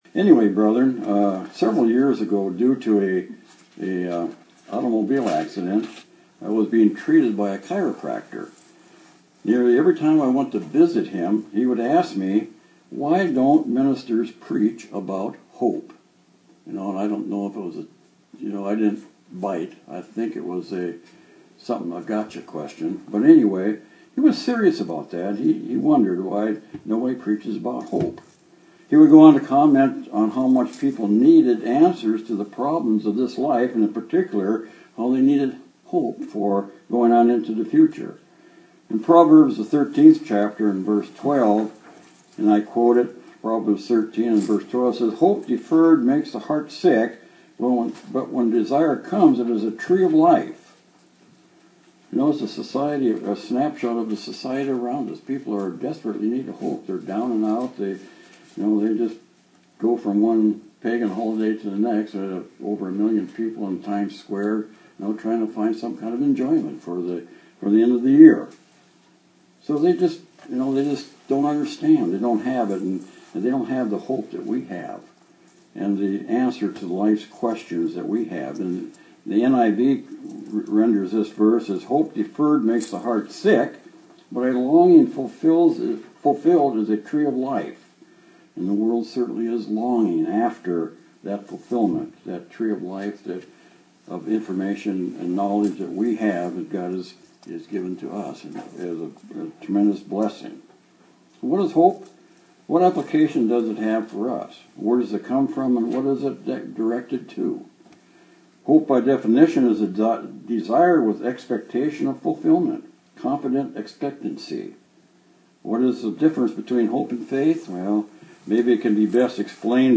Given in Austin, TX
UCG Sermon Studying the bible?